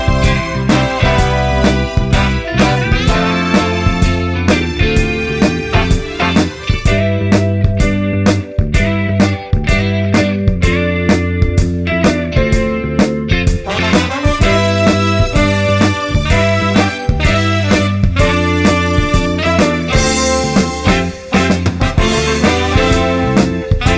no Backing Vocals Disco 3:20 Buy £1.50